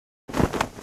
音效
人马分离.wav